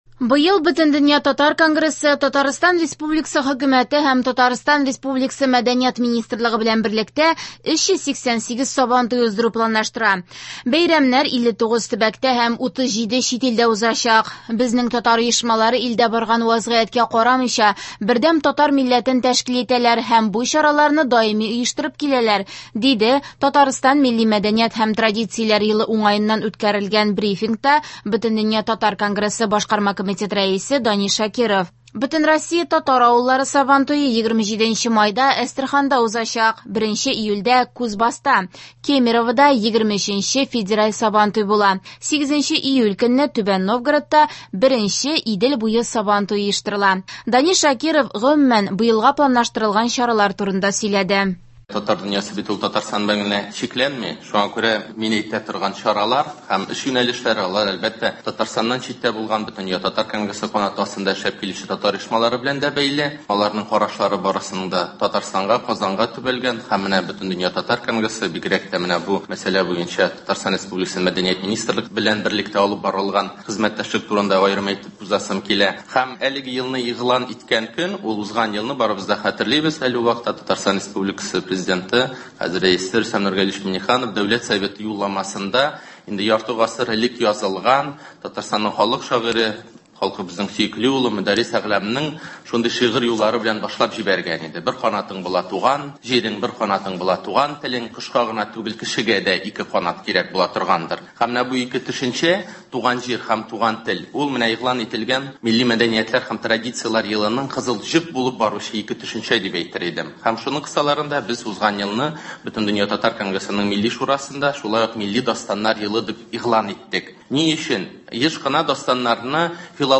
Яңалыклар (27.02.23)